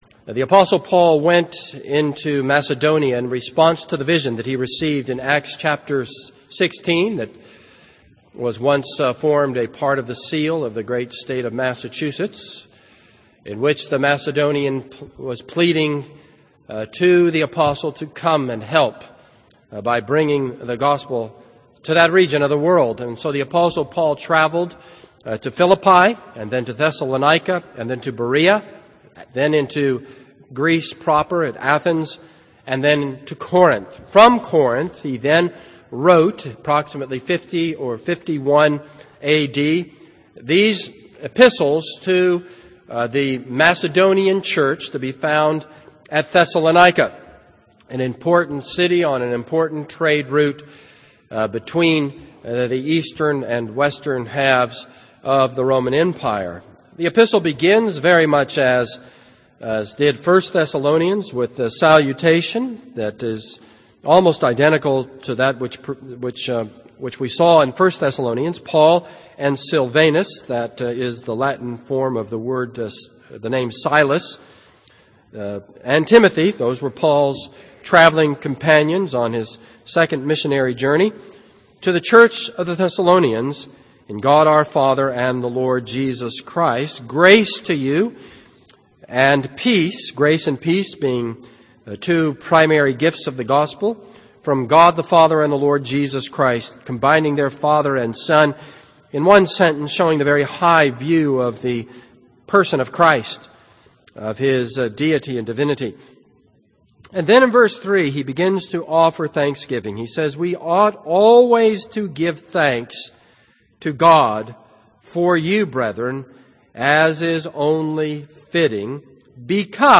This is a sermon on 2 Thessalonians 1:1-4.